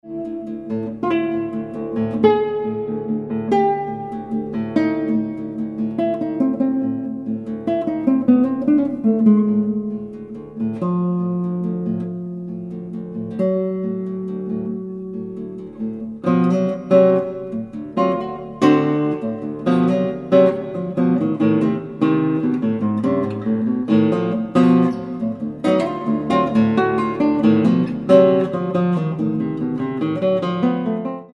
intimacies of color and liquid tone."